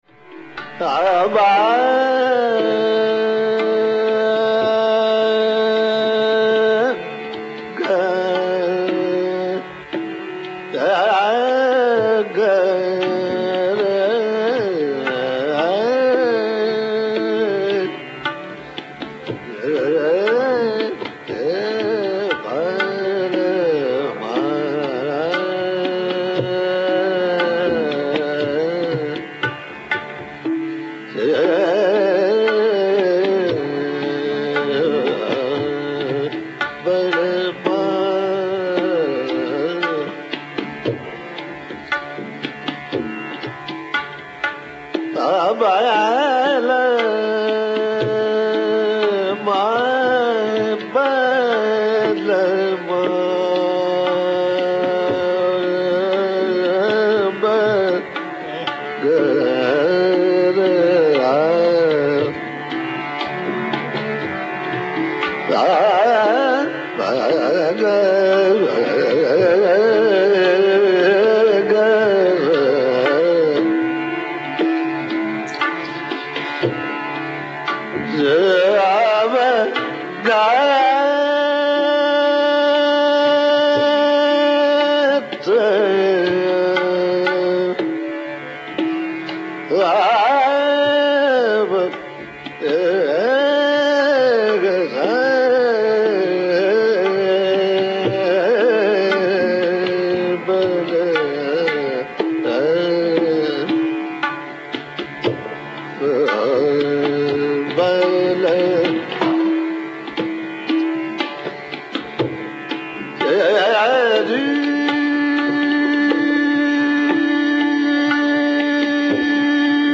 Amir Khan’s rendition is komal nishad dominant.
Notice the blip of shuddha nishad at 2:07.